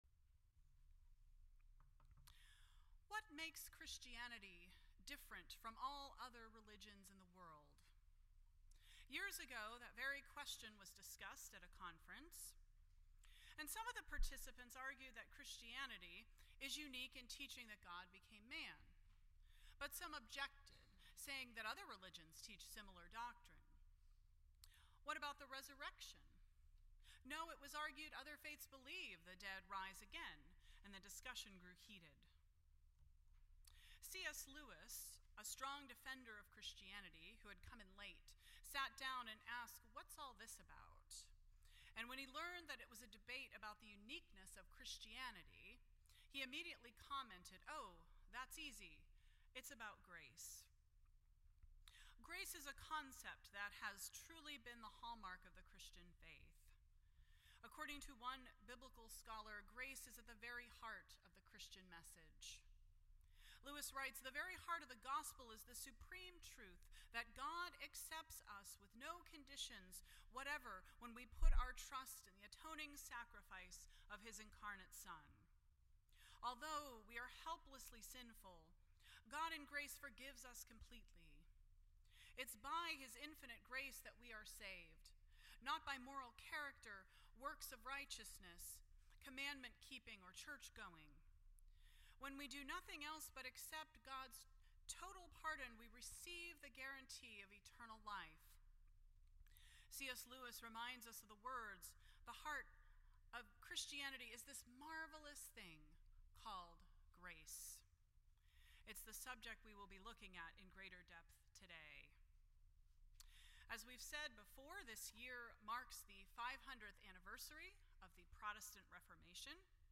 The Five Solas (Onlys) of the Protestant Reformation Service Type: World Communion Sunday %todo_render% Share This Story